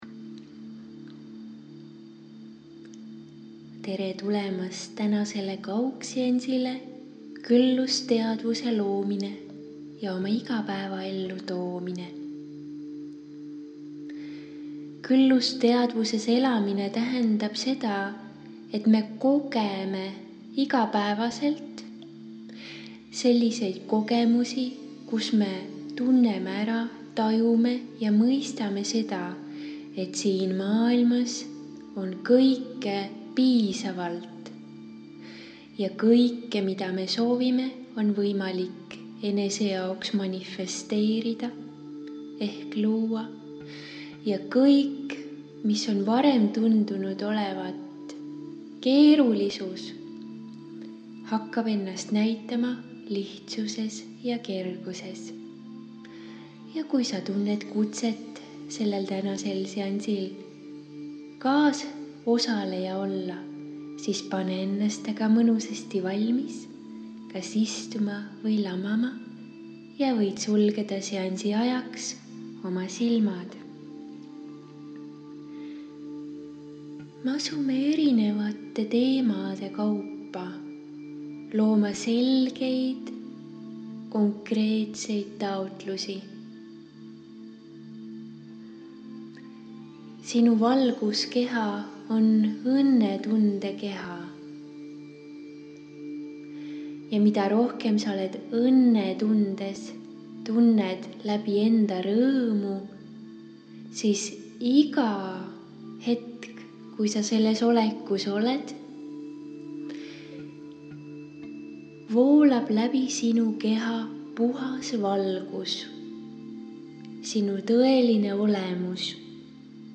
SIIT LEHELT SAAD KUULATA: MEDITATSIOONI KÜLLUSTEADVUSE PROGRAMMID HÄÄLESTA OMA ALATEADVUS RIKKUSE JA KÜLLUSE LAINELE salvestatud aastal 2018 *** Küllusteadvuse programmid on salvestatud energiaseanss alateadvuse ümber-programmeerimiseks aastal 2018.